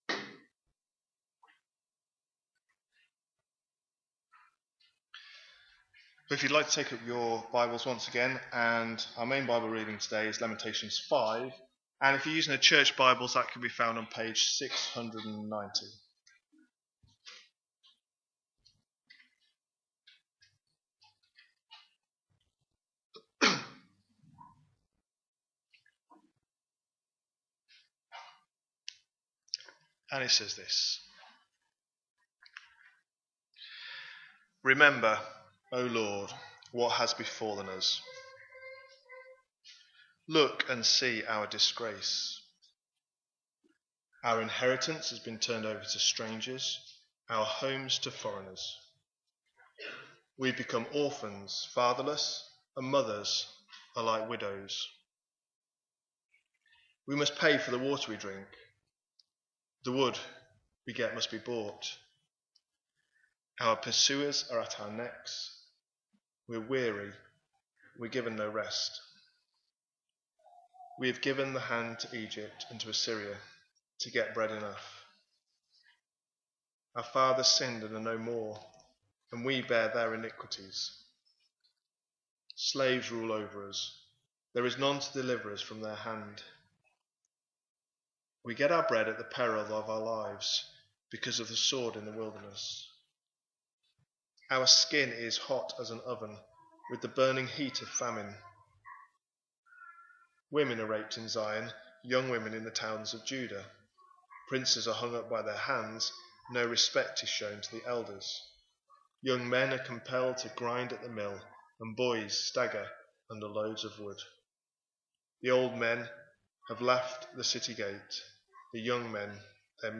A sermon preached on 6th April, 2025, as part of our Lamentations series.